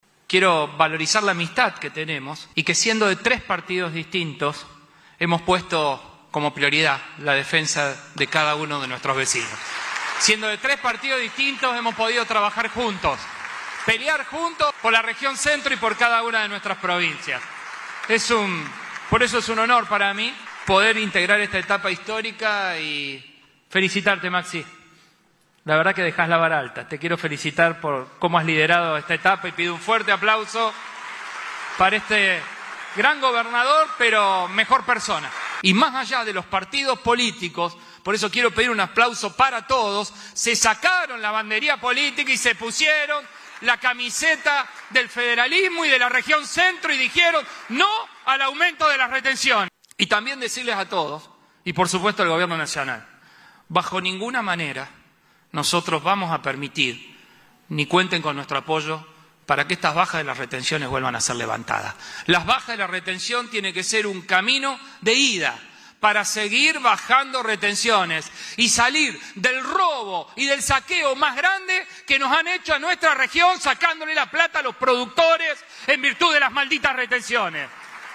Con el lema “Un modelo de desarrollo desde el interior productivo”, se llevó a cabo en la ciudad de Santa Fe la XVIII Reunión Institucional de la Región Centro.
MARTIN-LLARYORA-GOB-CORDOBA-ASUME-PRESIDENCIA-PRO-TEMPORE-REGION-CENTRO.mp3